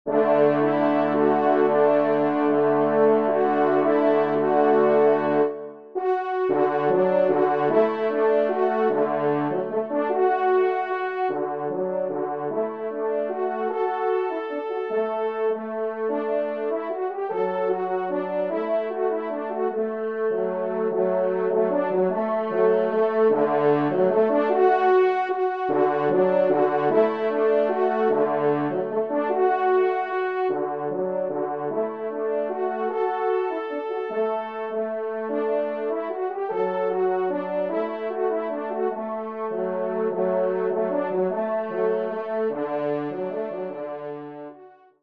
Genre :  Divertissement pour Trompes ou Cors en Ré et Picolo
3e Trompe